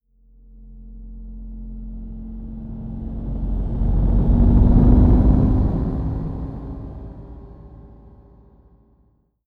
atterrissage.wav